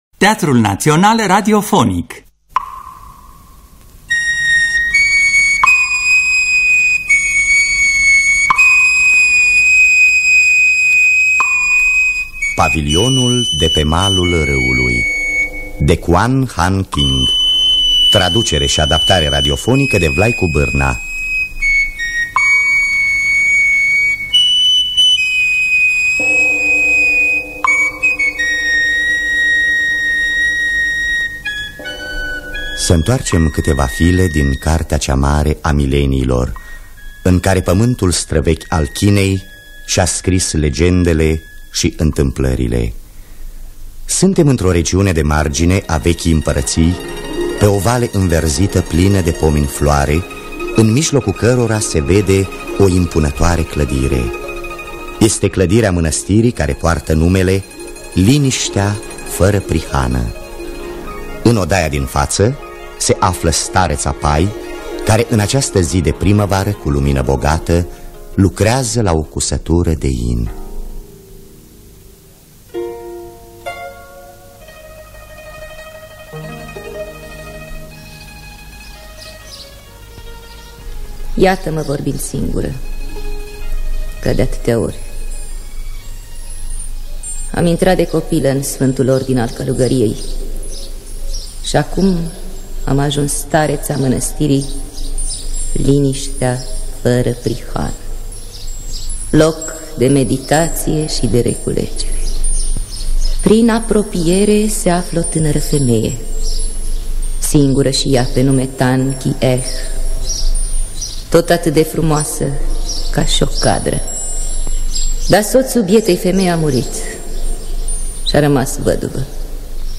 Pavilionul de pe malul râului de Kuan Han-Ching – Teatru Radiofonic Online
Traducerea și adaptarea radiofonică de Vlaicu Bârna.